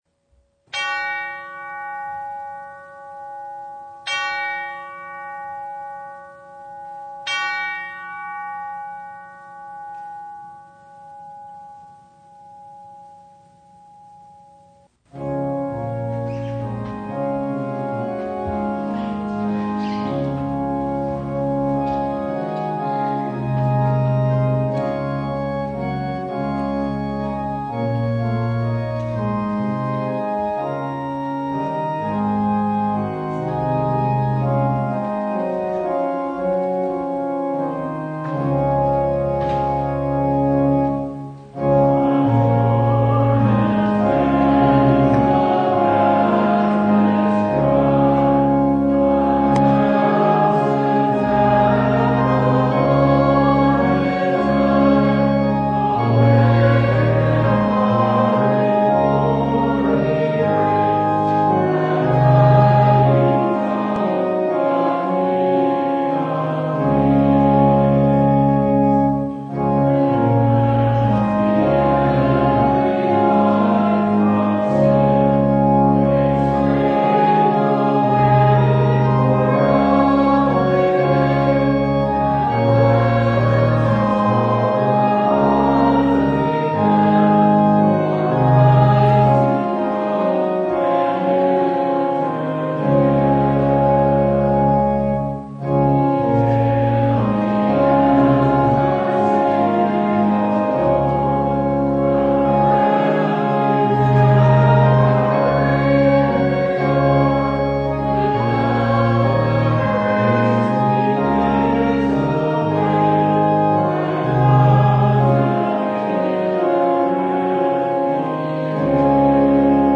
Mark 1:1-8 Service Type: Advent The way of the Lord begins with repentance and faith.